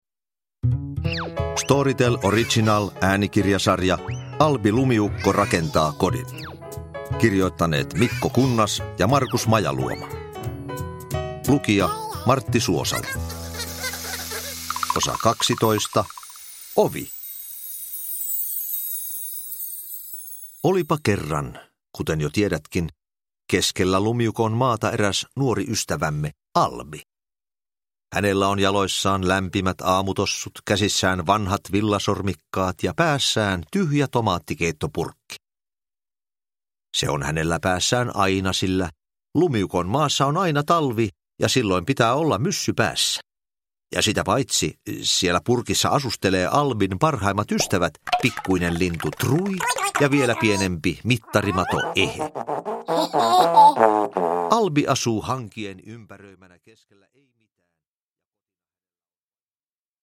Uppläsare: Martti Suosalo